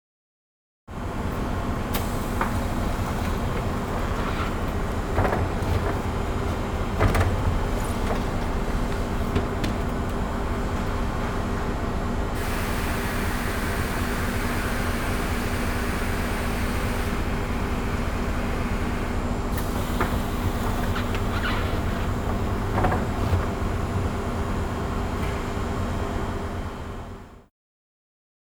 機械のモーター音やアナウンス、発車の合図......。地下鉄、都電荒川線、都営バスのそれぞれの場所でしか聞くことができない音を収録しました。
第6回都営バス「ニーリング音」
第6回 都営バス「ニーリング音」 乗り降りがしやすいように設計された「ノンステップバス」の、乗車口が下がる際にでるエアー音。